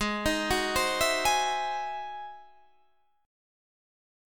Ab7b13 Chord